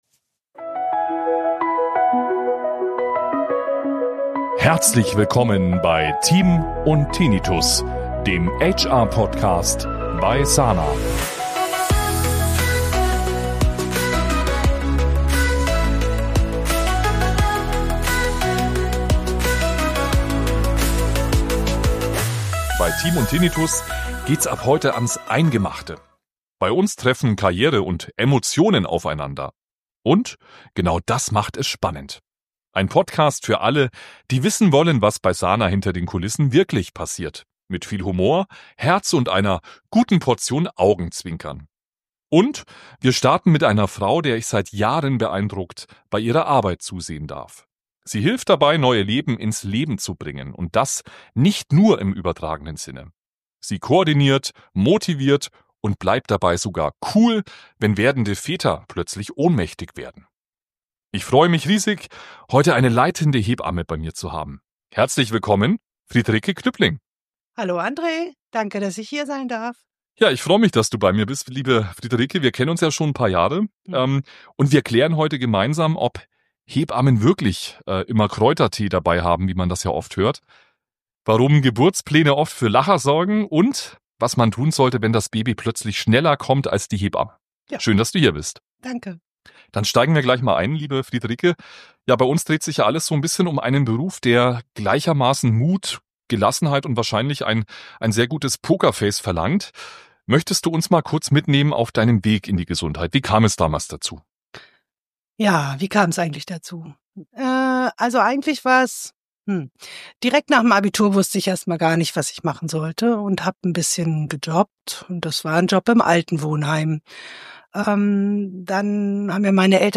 Es wird informativ, emotional und garantiert unterhaltsam!